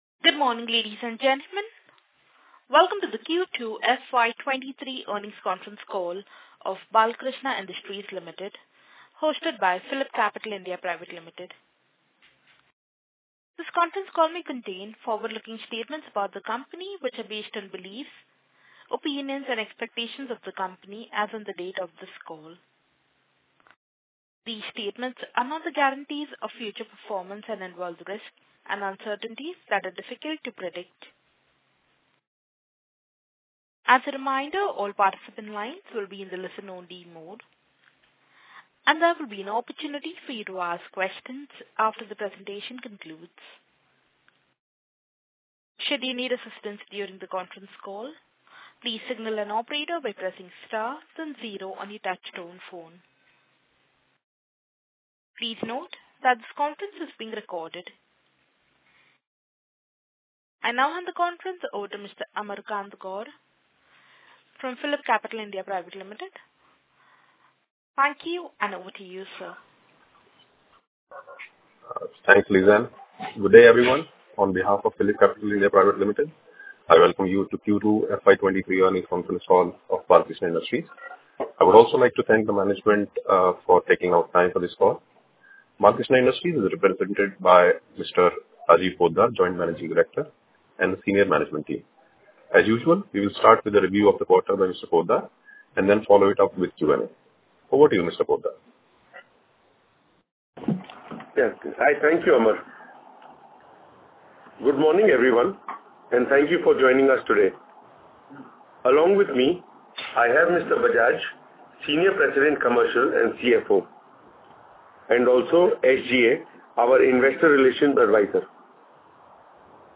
Webcast and Transcript of Conference Call
Audio recordings of conference Call dated 15th November, 2022